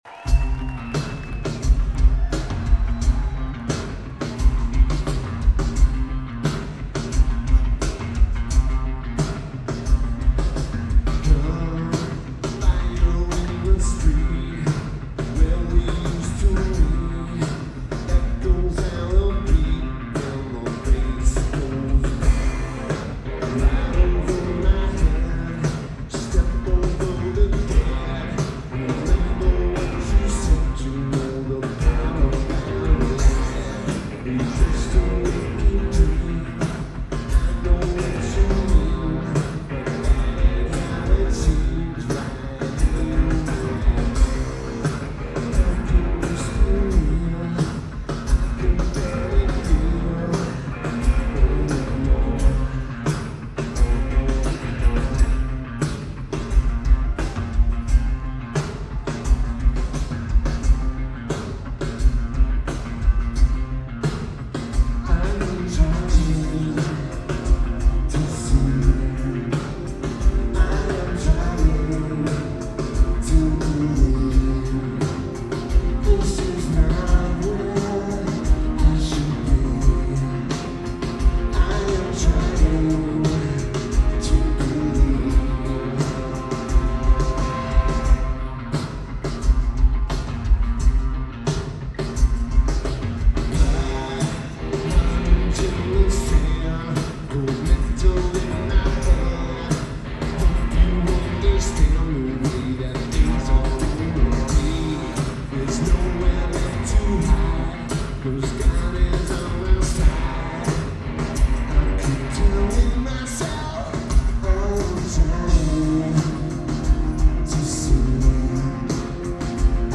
Wang Theatre at Boch Center
Lineage: Audio - AUD (CA14 Card + SP-SPSB-8-MKII + Zoom H1)